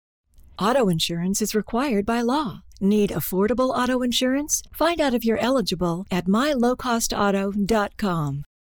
CDI Phone Hold Messages